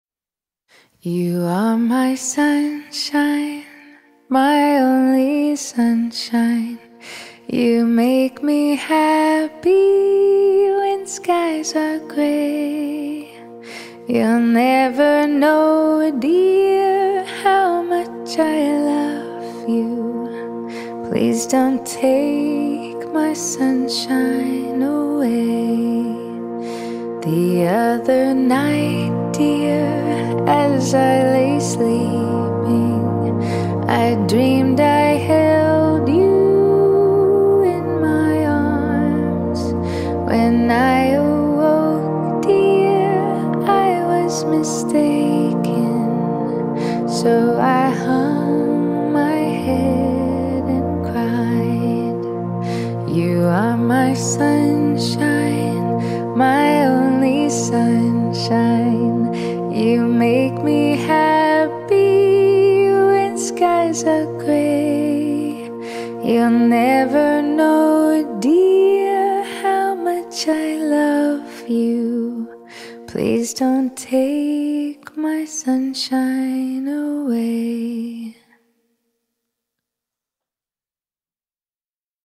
Children Song
Solo Recorder